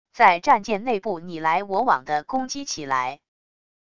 在战舰内部你来我往的攻击起来wav音频